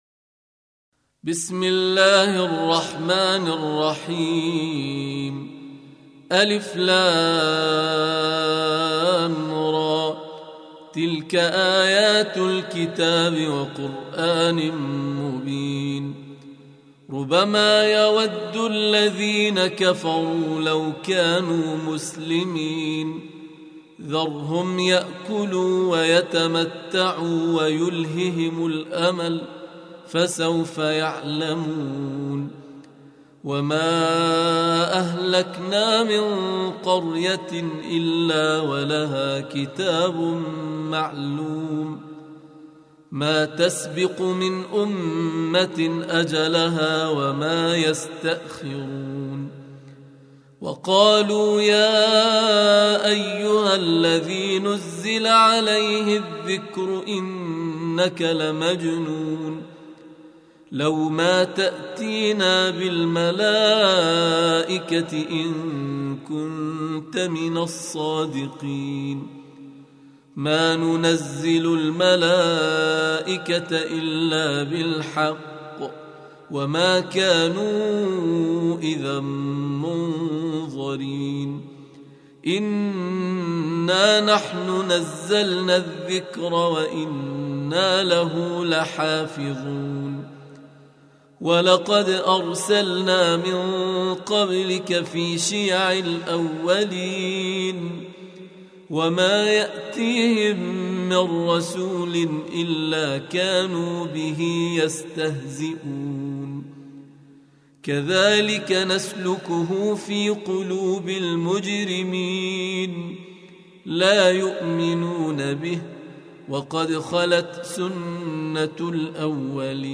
Surah Repeating تكرار السورة Download Surah حمّل السورة Reciting Murattalah Audio for 15. Surah Al-Hijr سورة الحجر N.B *Surah Includes Al-Basmalah Reciters Sequents تتابع التلاوات Reciters Repeats تكرار التلاوات